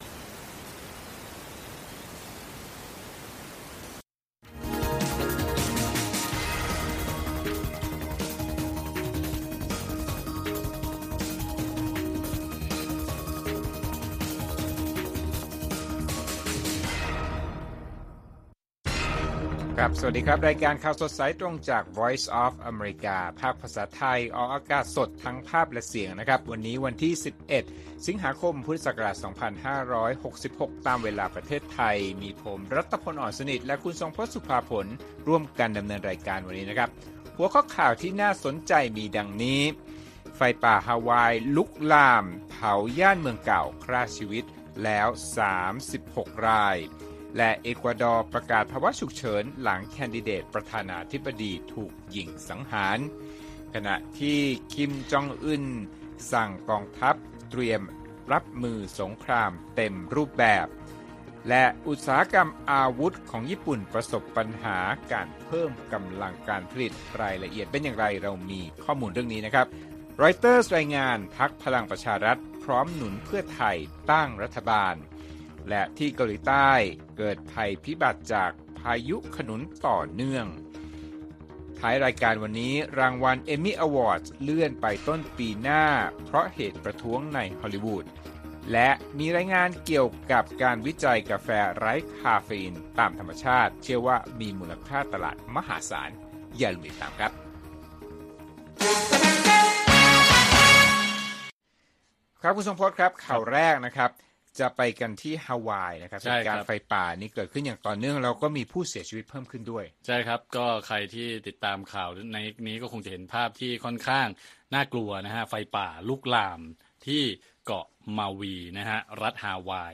ข่าวสดสายตรงจากวีโอเอไทย 6:30 – 7:00 น. วันที่ 11 ส.ค. 2566